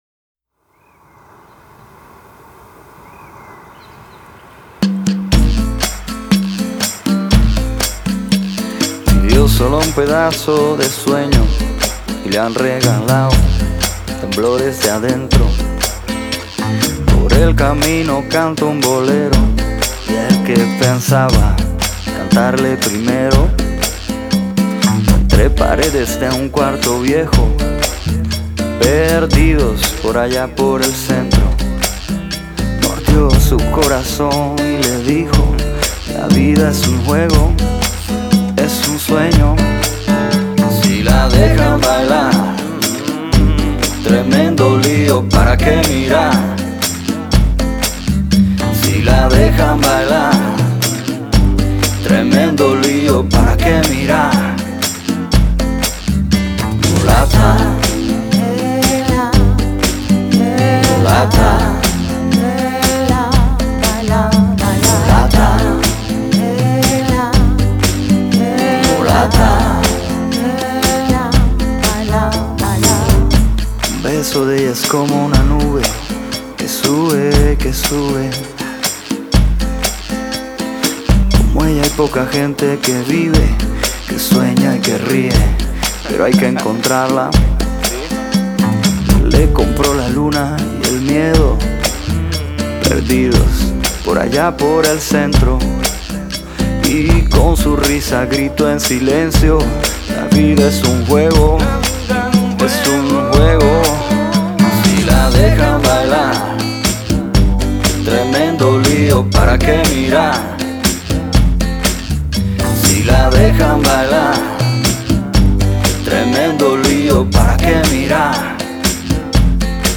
a Cuban singer
It's an ultimate good mood song for me.